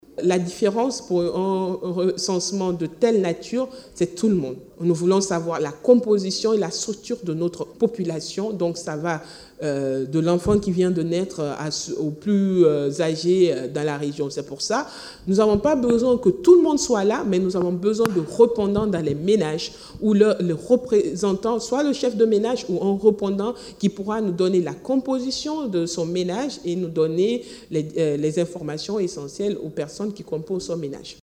La Ministre du Plan et de la Coopération Internationale explique que ce processus concerne toute la population, tout âge confondu.